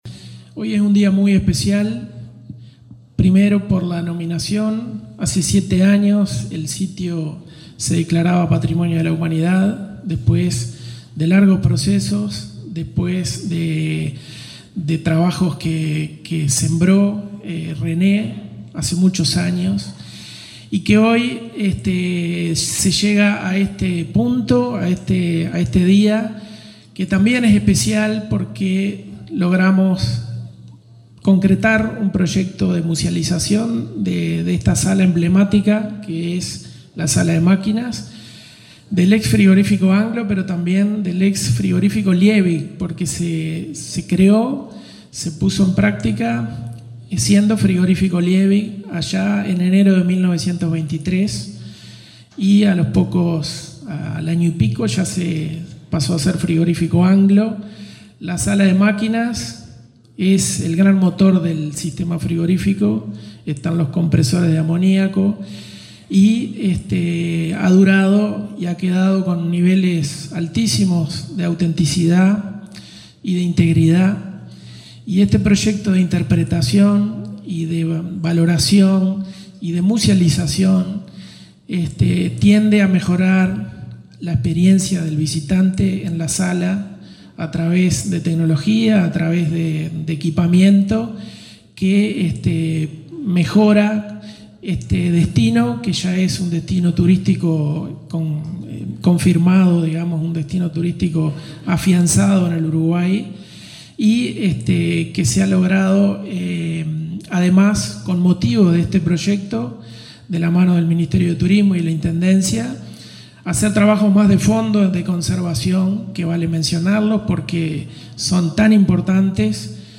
Palabras de autoridades en Río Negro